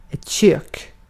Uttal
Uttal Okänd accent: IPA: /ɕøːk/ IPA: /çøːk/ Ordet hittades på dessa språk: svenska Översättning 1. mutfak Artikel: ett .